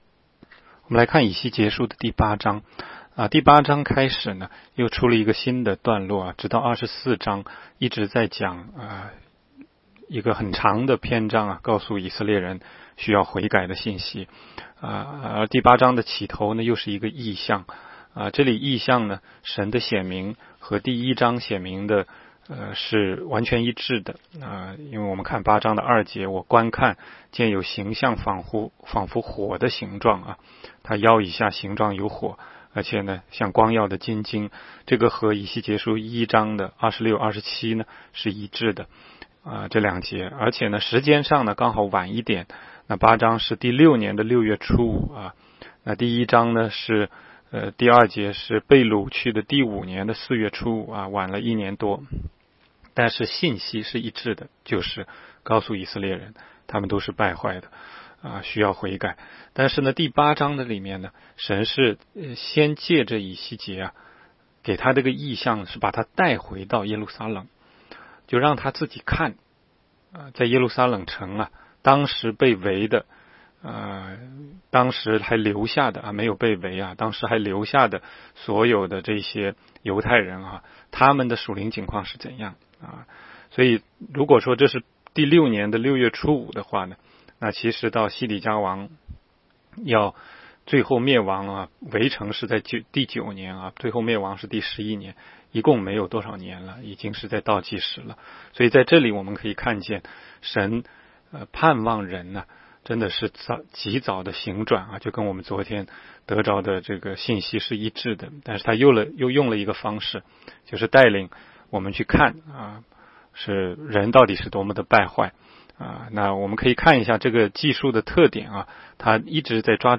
16街讲道录音 - 每日读经 -《以西结书》8章